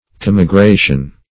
Search Result for " commigration" : The Collaborative International Dictionary of English v.0.48: Commigration \Com`mi*gra"tion\, n. [L. commigratio.] Migration together.